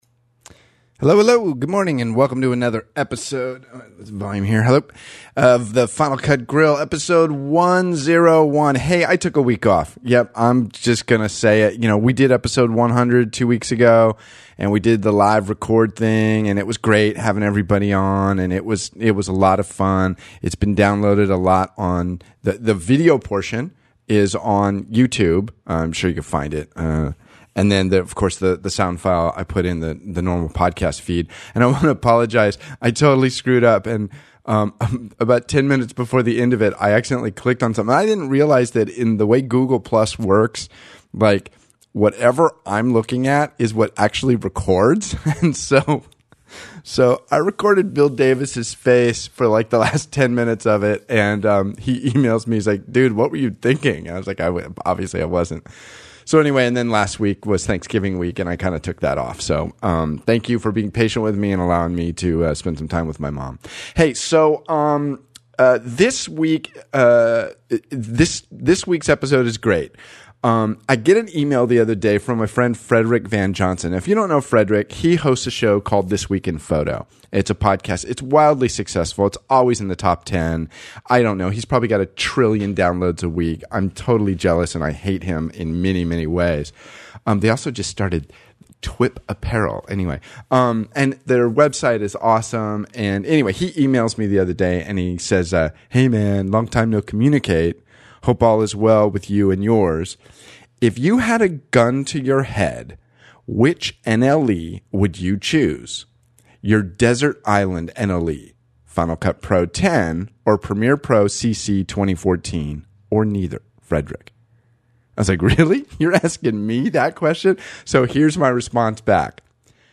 This conversation is a result of a handful of eMails one day and discusses some beginners questions regarding FCPX.